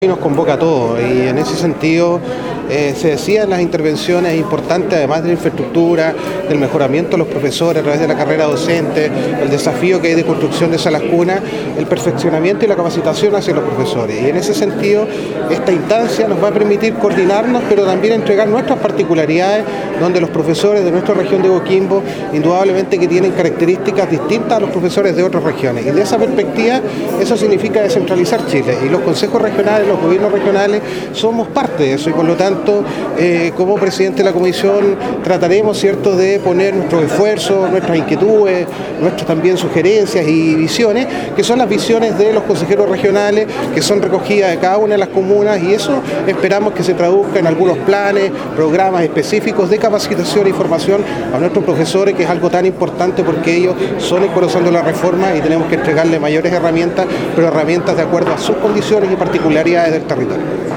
denis_cortes__consejero_regional_.mp3